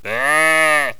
sheep5.wav